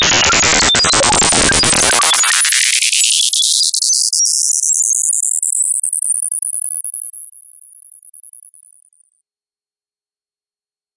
描述：乱七八糟的响声。 使用modulo来提供早期Atari的声音。
Tag: 音频技术中 光点 FM-合成 ITP-2007 maxmsp 噪声